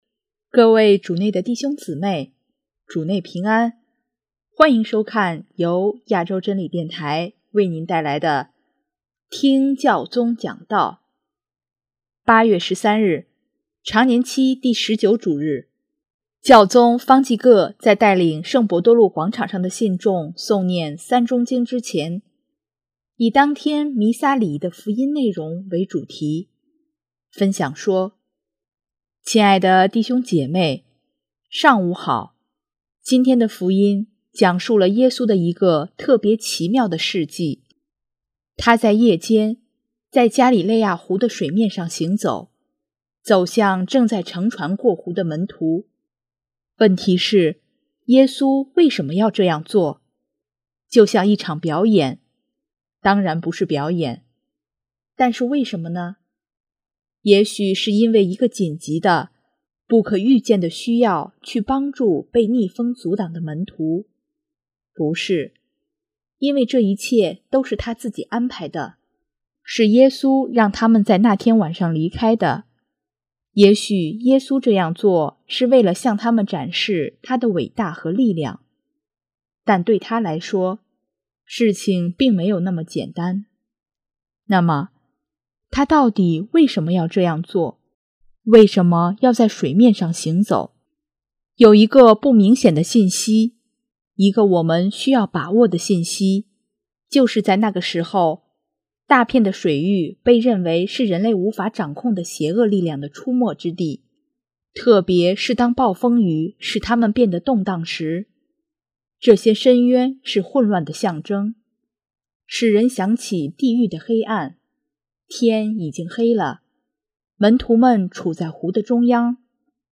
8月13日，常年期第十九主日，教宗方济各在带领圣伯多禄广场上的信众诵念《三钟经》之前，以当天弥撒礼仪的福音内容为主题，分享说：